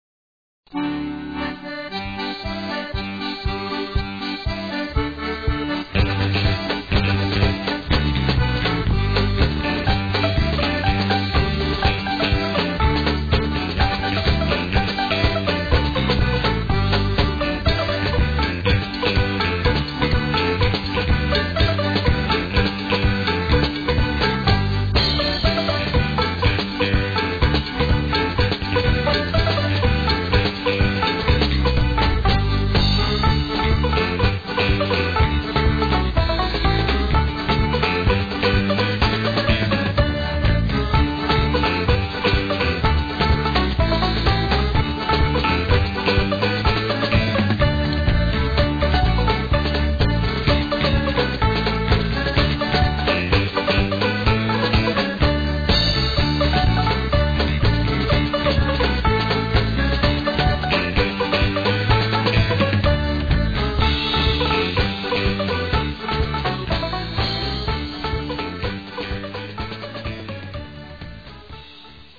7x32 Reels